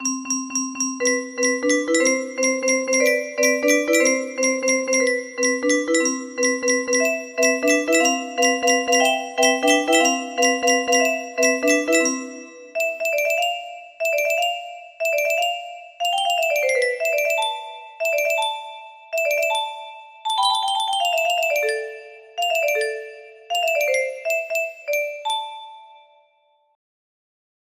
Wow! It seems like this melody can be played offline on a 15 note paper strip music box!